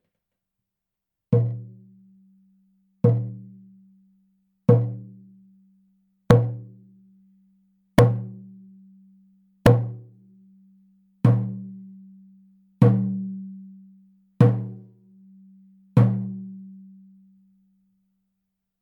ネイティブ アメリカン（インディアン）ドラム NATIVE AMERICAN (INDIAN) DRUM 16インチ（deer 鹿）
ネイティブアメリカン インディアン ドラムの音を聴く
乾いた張り気味の音です